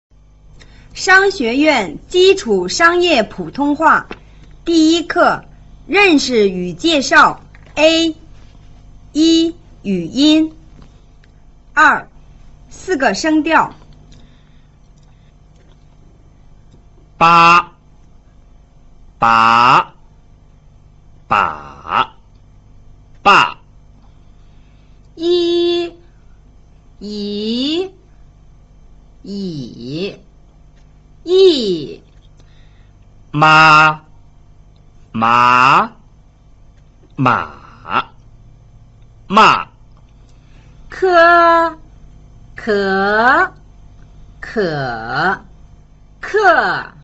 (2) 四個聲調